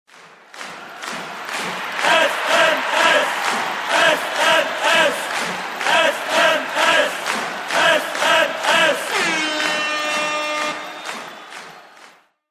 جلوه های صوتی